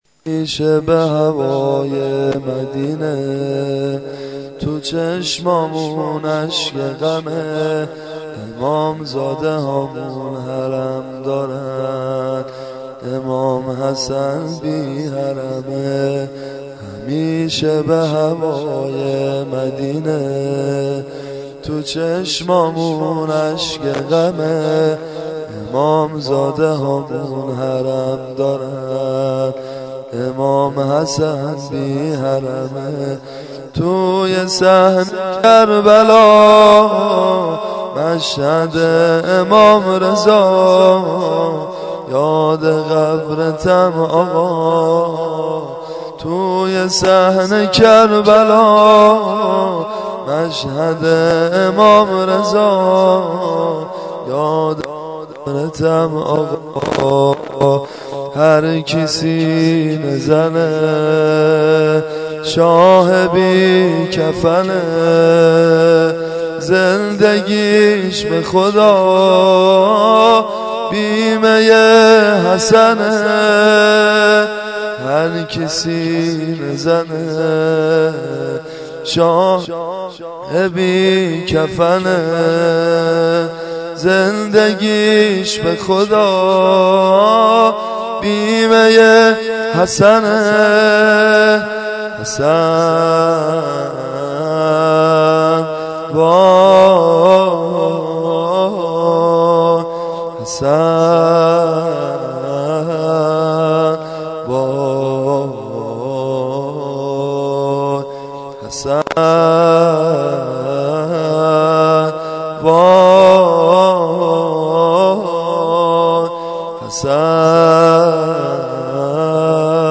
دانلود متن شهادت امام حسن(ع)+ سبک زمینه